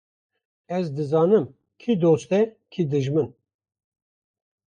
Pronounced as (IPA)
/doːst/